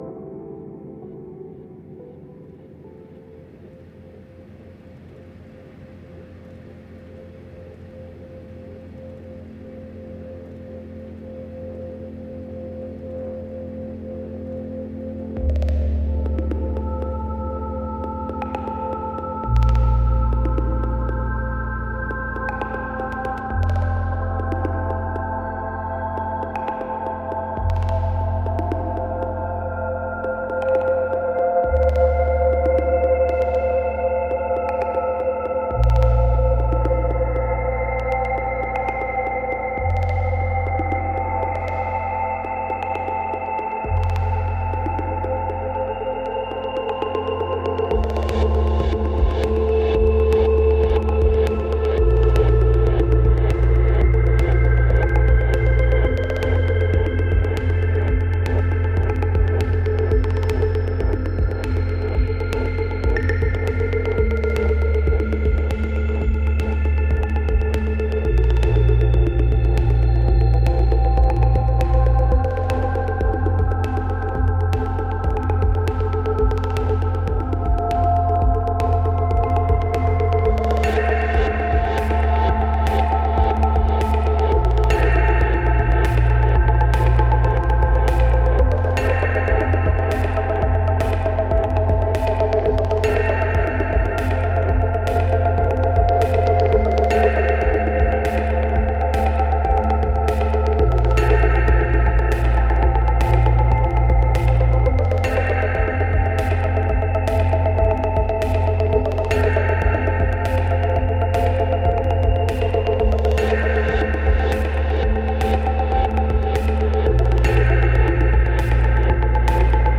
Intriguing ambient music telling tales from south africa.